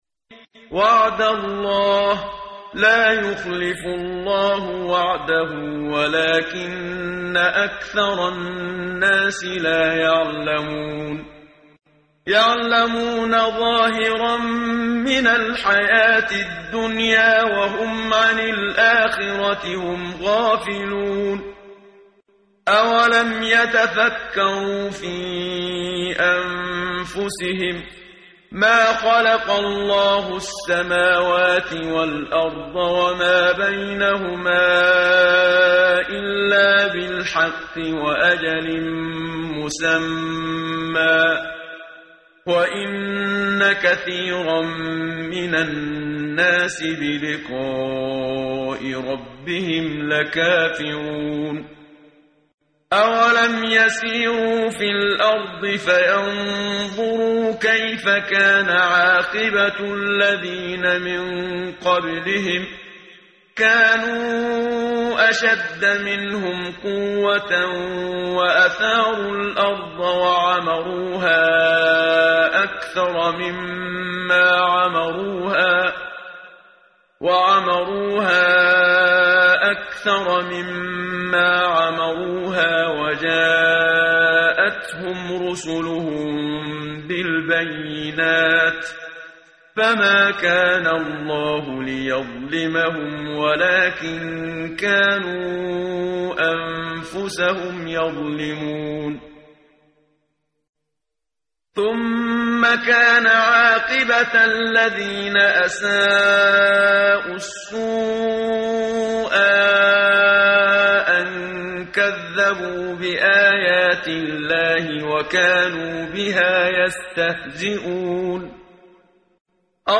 قرائت قرآن کریم ، صفحه 405، سوره مبارکه الروم آیه 6 تا 15 با صدای استاد صدیق منشاوی.
کیفیت صوت خیلی پایین بود